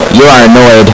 and is REALLY annoying.